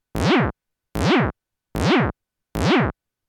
TD-3-TG zap x4
303 acid bass bounce club dance drop dub sound effect free sound royalty free Music